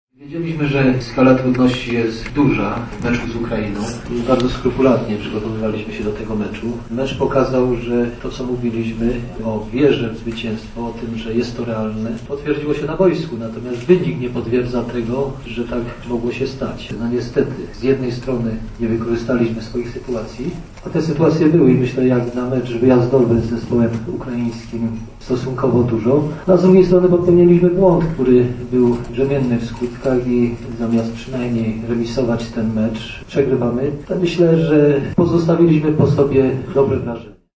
Selekcjoner reprezentacji Polski, Waldemar Fornalik, przyznał na pomeczowej konferencji prasowej, iż jego podopieczni pozostawili po tym spotkaniu dobre wrażenie:
Wlademar-Fornalik-konferencja-prasowa-komentarz-do-meczu1.mp3